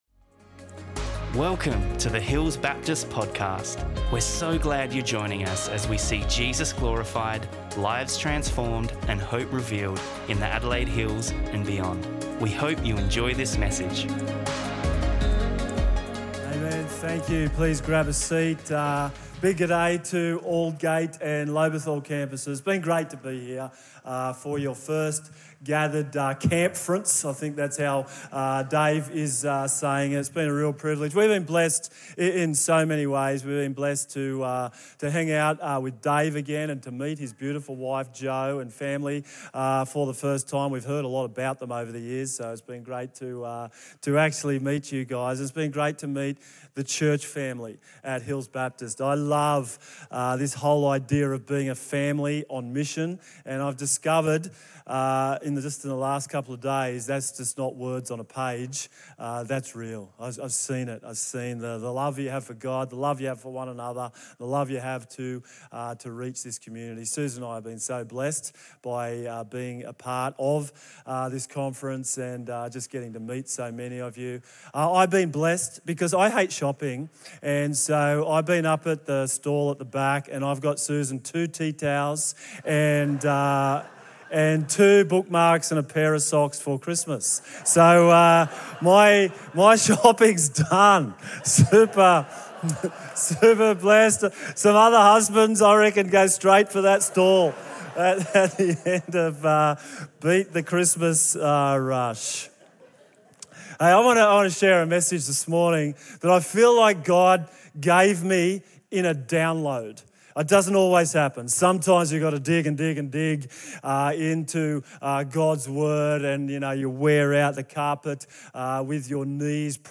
be generous sermon.mp3